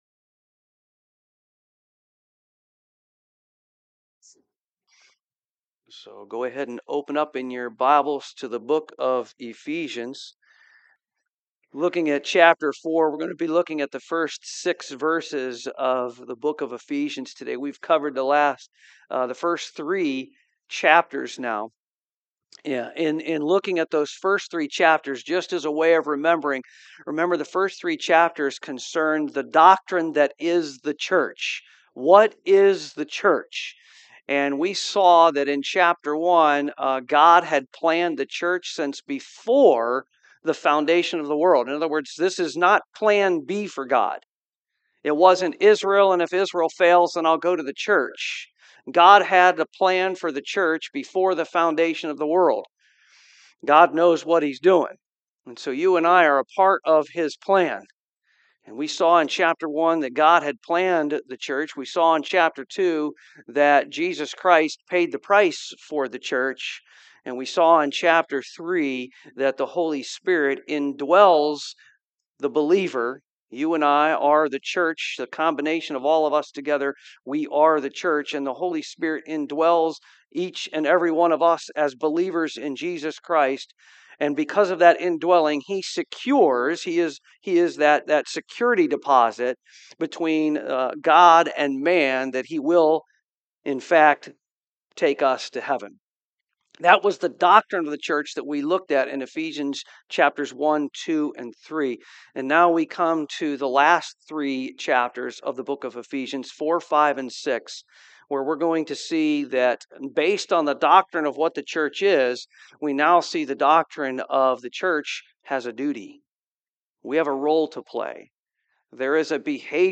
Ephesians 4:1-6 Service Type: AM Are you called Christian?